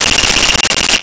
Cell Phone Ring 14
Cell Phone Ring 14.wav